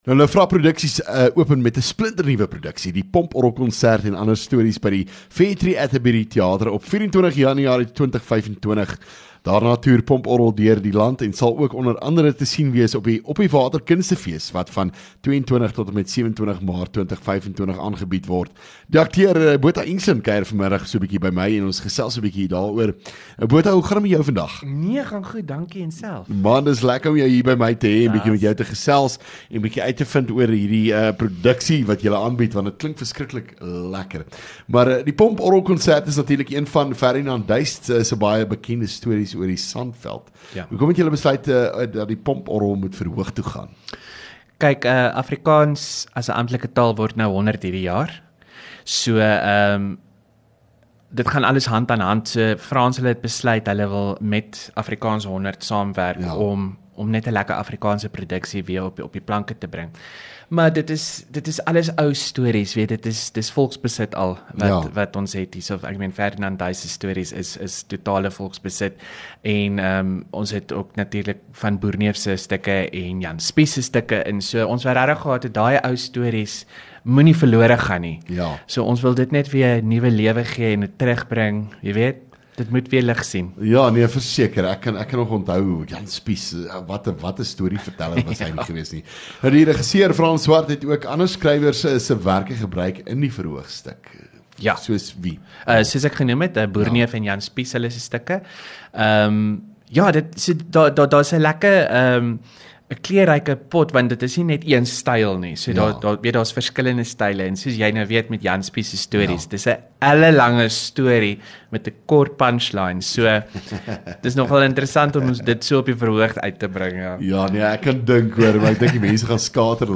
LEKKER FM | Onderhoude 22 Jan Die Pomporrelkonsert